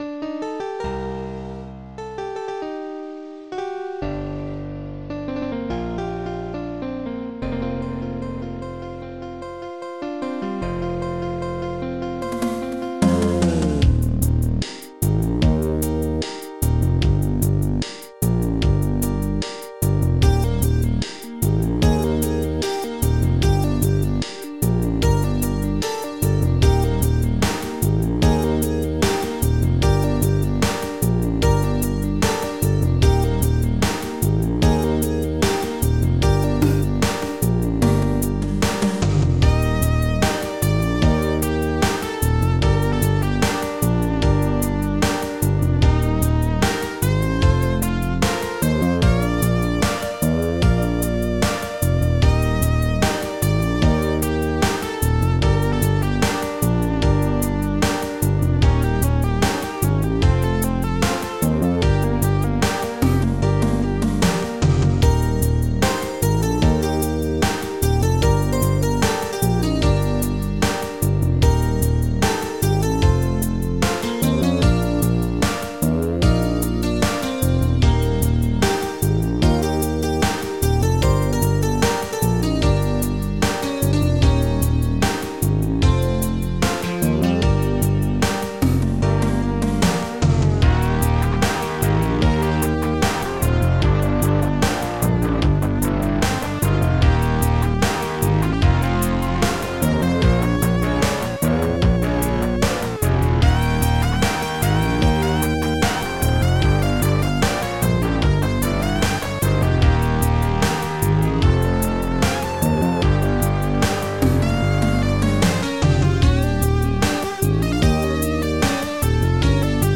Protracker and family
grandpiano1
osterm1bass1
st-34:34hihat
ST-67:guitar93